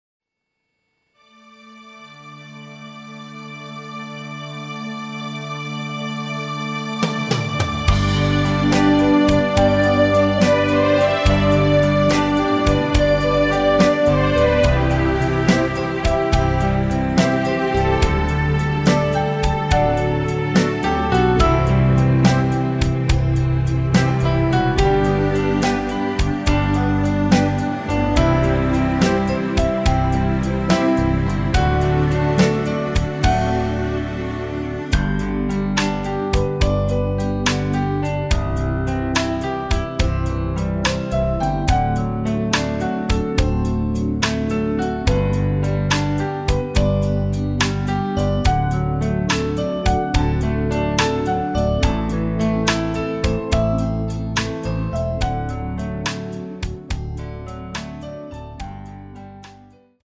발매일 1989.08 원키에서(-8)내린 MR 입니다.
앞부분30초, 뒷부분30초씩 편집해서 올려 드리고 있습니다.
중간에 음이 끈어지고 다시 나오는 이유는
위처럼 미리듣기를 만들어서 그렇습니다.